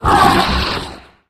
4aef571f59 Divergent / mods / Soundscape Overhaul / gamedata / sounds / monsters / bloodsucker / hit_6.ogg 11 KiB (Stored with Git LFS) Raw History
hit_6.ogg